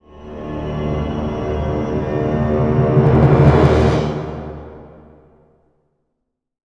Index of /90_sSampleCDs/Best Service ProSamples vol.33 - Orchestral Loops [AKAI] 1CD/Partition C/CRESCENDOS